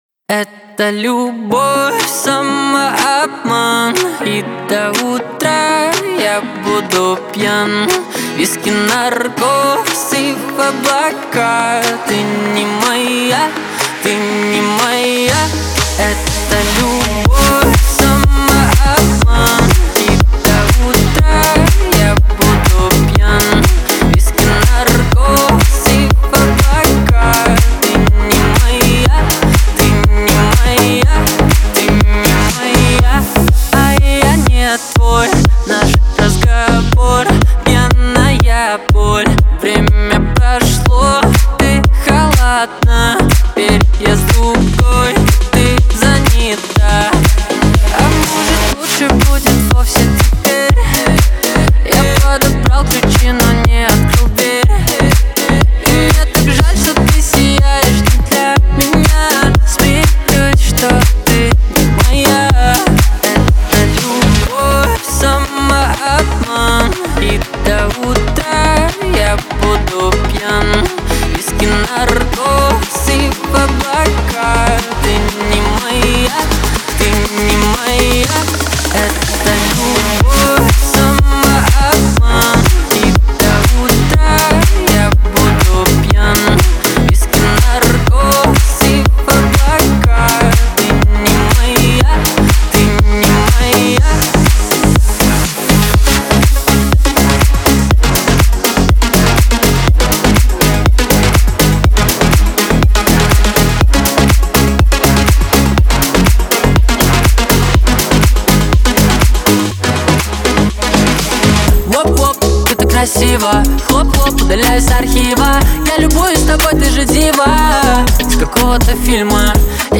Категория: Танцевальная музыка
dance треки , весёлые песни